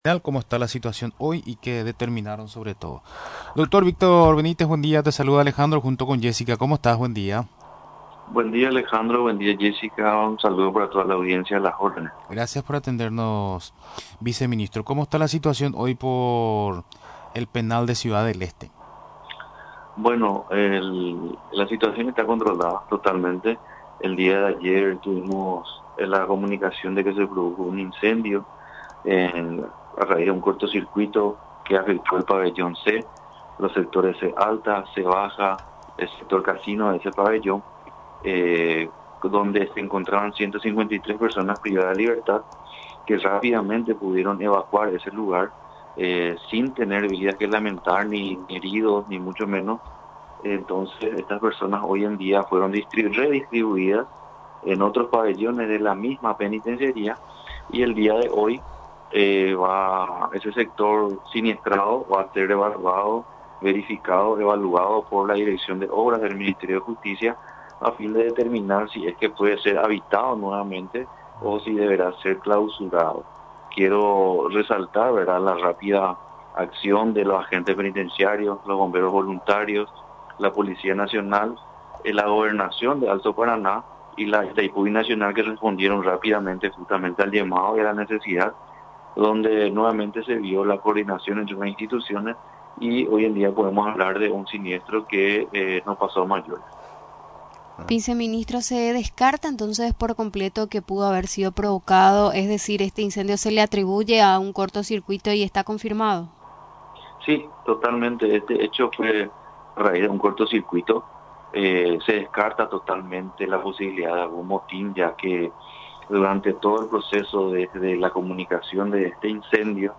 Durante la entrevista en Radio Nacional del Paraguay, mencionó que la ITAIPU Binacional y la Gobernación del Alto Paraná, proveyeron colchones para las personas privadas de libertad, que perdieron sus cosas personales durante el incendio.